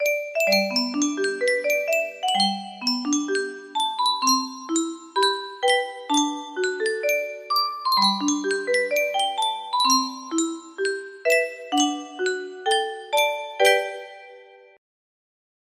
Yunsheng Music Box - Unknown Tune 1084 music box melody
Full range 60